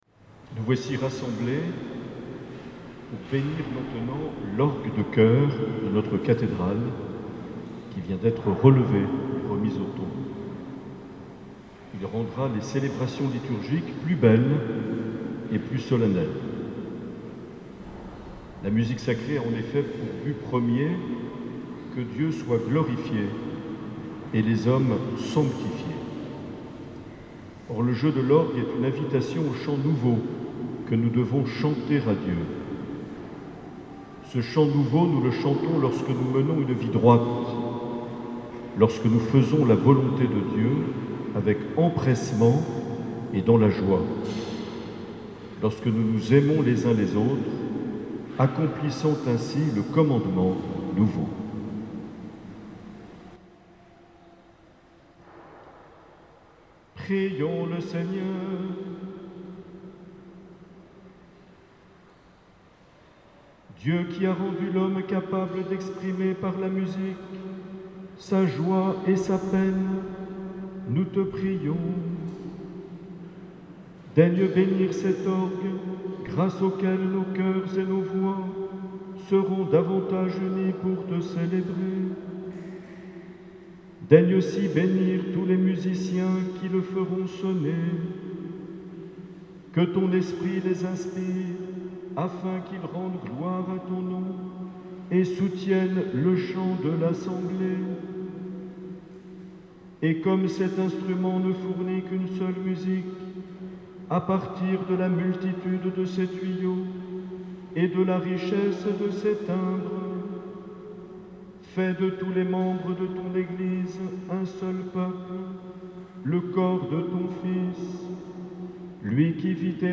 Bénédiction de l’orgue de choeur relevé de la cathédrale de Bayonne
Dimanche 13 décembre 2020 lors de la messe du 3ème Dimanche de l’Avent présidée par Mgr Marc Aillet.